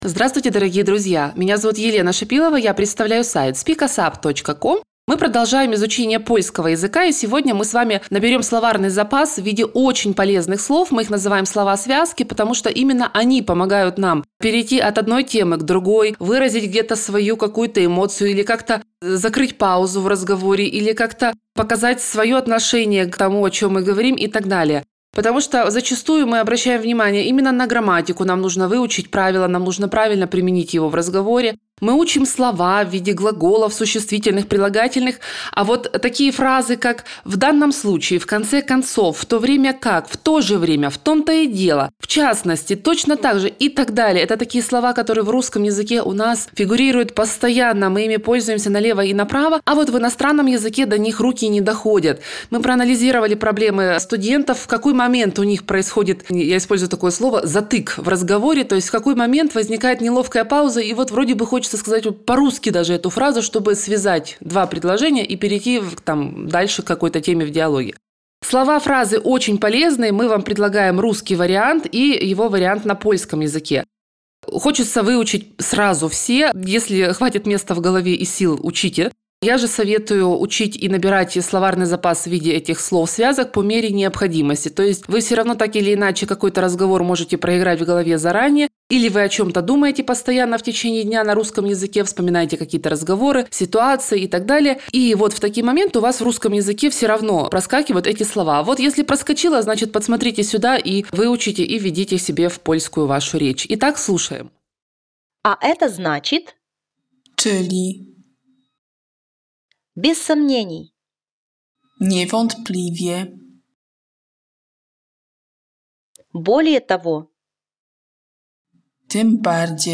  Прослушайте аудио урок с дополнительными объяснениями